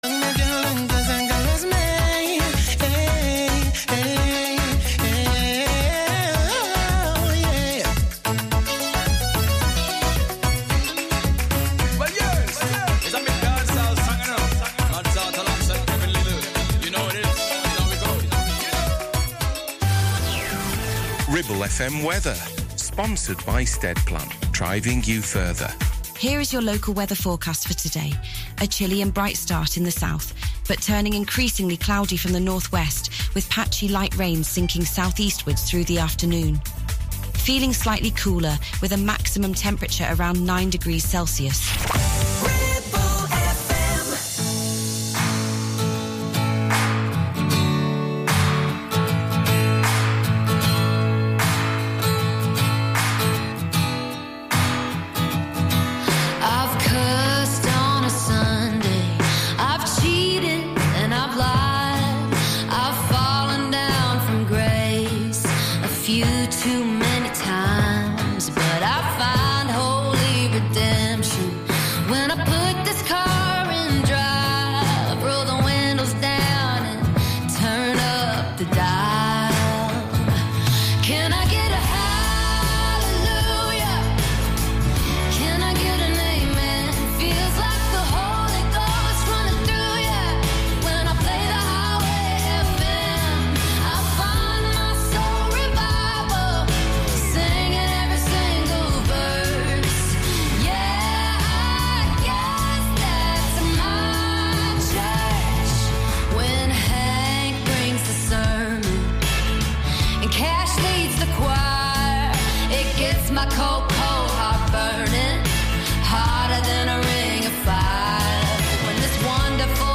Some of the finest music from the country scene, modern and classics all together